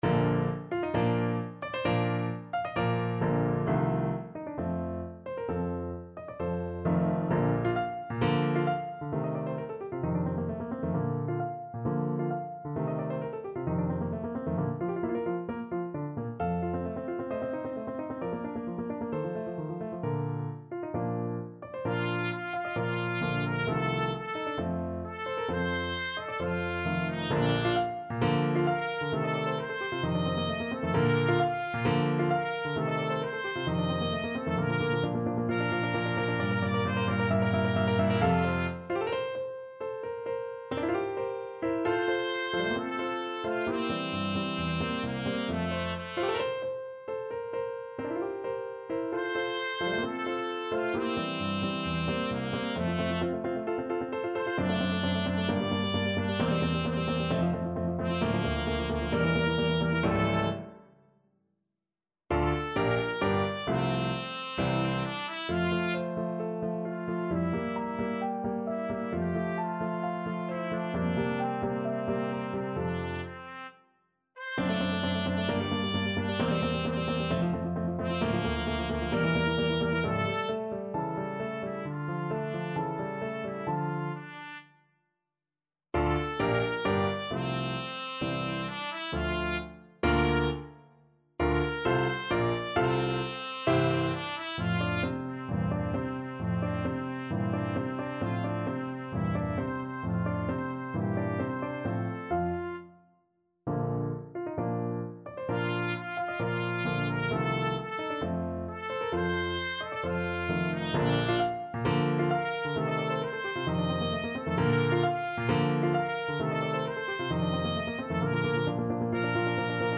~ = 132 Allegro (View more music marked Allegro)
4/4 (View more 4/4 Music)
Classical (View more Classical Trumpet Music)